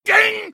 ding_01